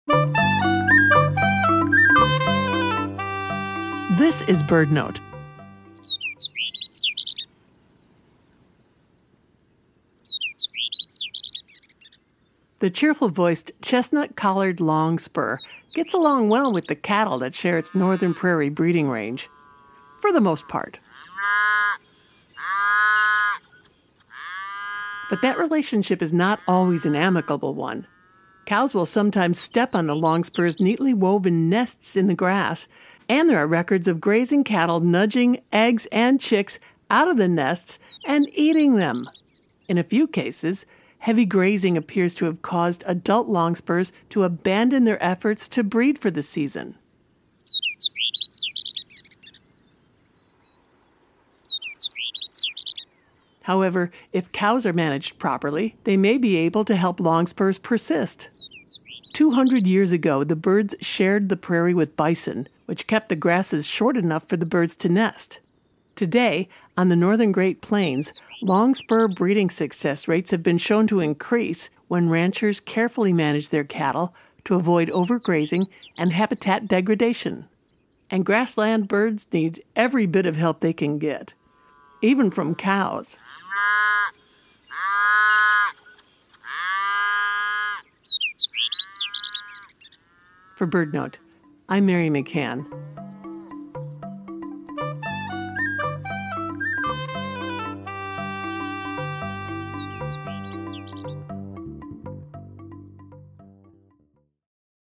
The cheerful-voiced Chestnut-collared Longspur shares their northern prairie breeding range with grazing cattle.